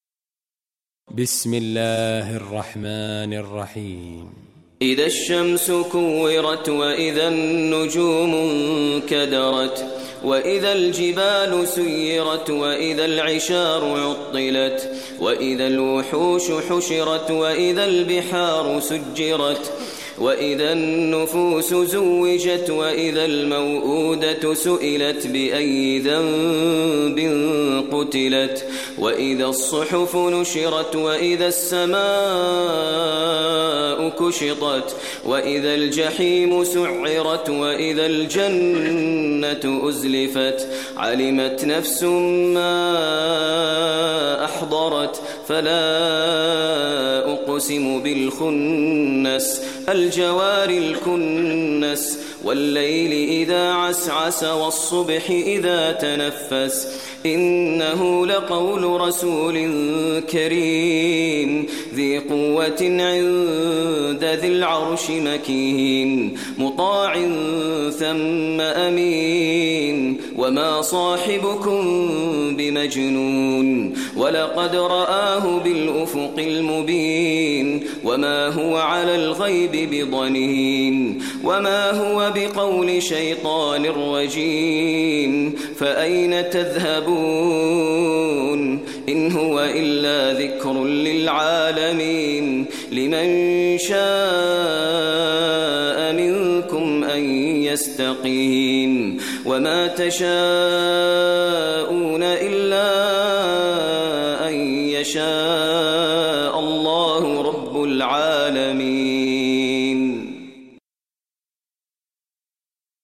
Surah Takwir Recitation by Maher al Mueaqly
Surah Takwir, listen online mp3 tilawat / recitation in Arabic recited by Imam e Kaaba Sheikh Maher al Mueaqly.